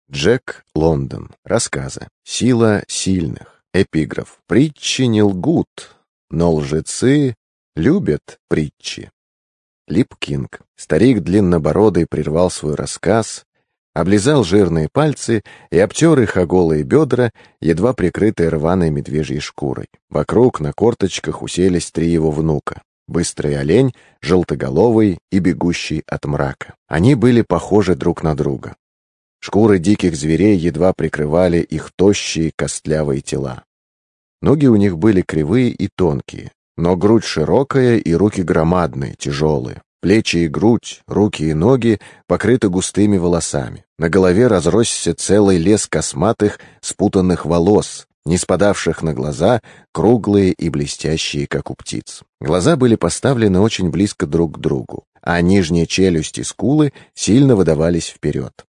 Аудиокнига Сила сильных. Рассказы | Библиотека аудиокниг